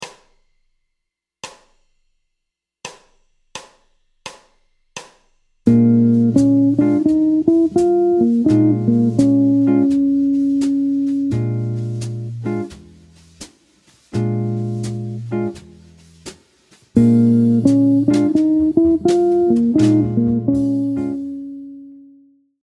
Motif Jazz : (E2) Chromatismes ascendants continus
Lick Jazz Blues #14
Gamme de Bb Blues à laquelle on ajoute, en mesure 1, la Tierce Majeure pour obtenir une ligne chromatique montante de la Tierce mineure à la Quinte.